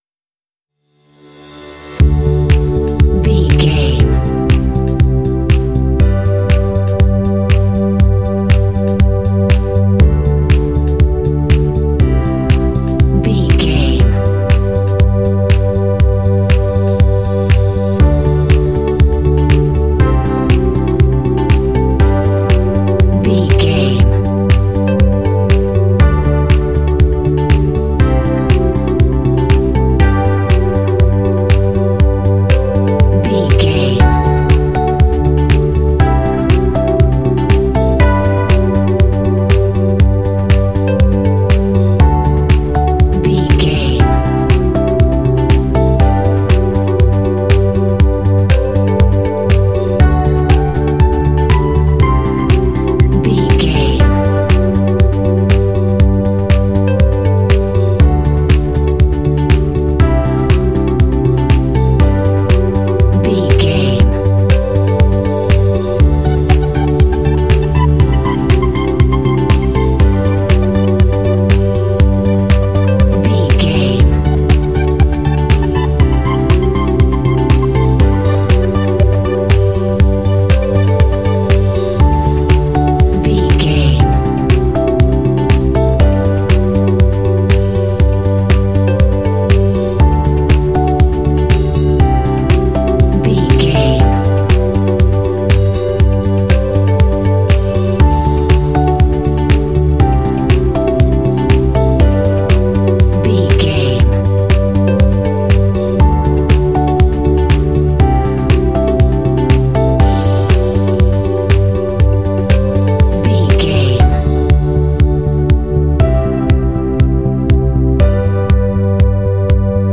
Uplifting
Dorian
D
bright
joyful
drums
synthesiser
electric piano
synth-pop
underscore